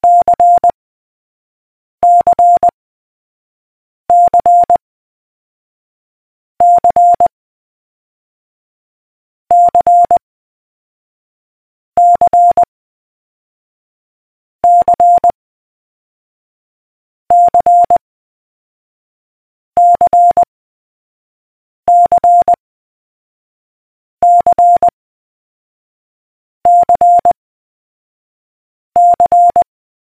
beacon.ogg